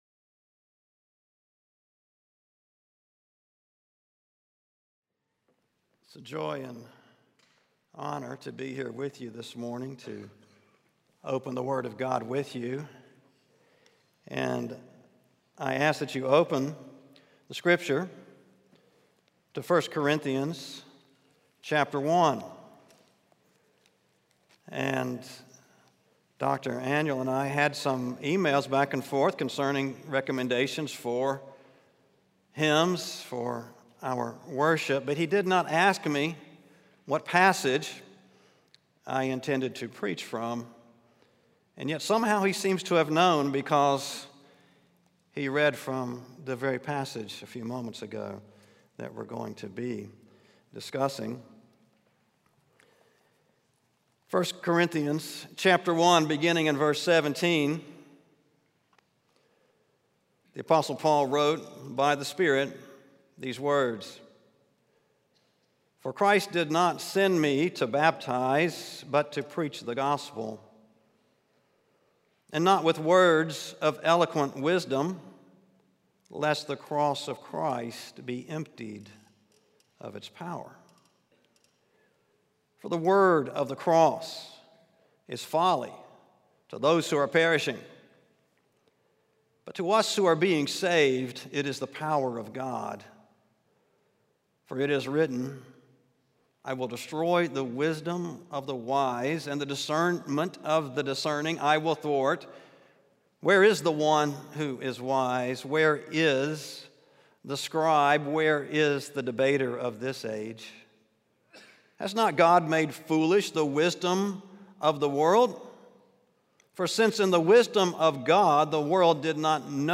in SWBTS Chapel
SWBTS Chapel Sermons